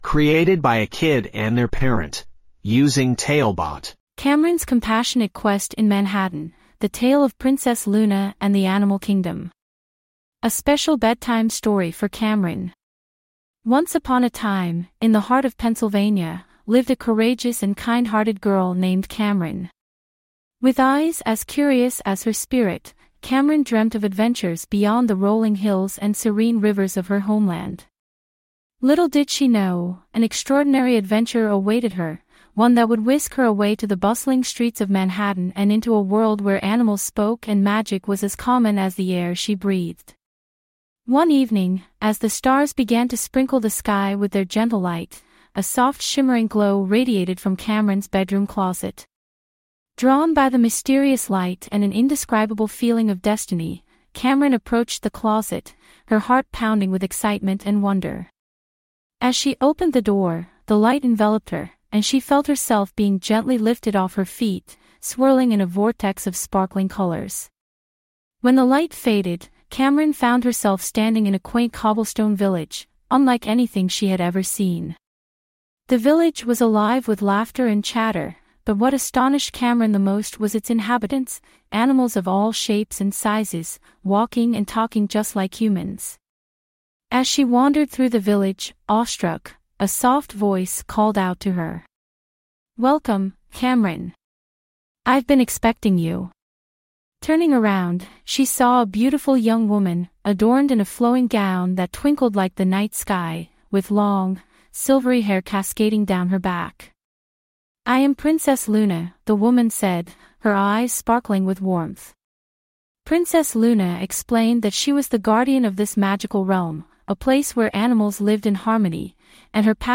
5 Minute Bedtime Stories
TaleBot AI Storyteller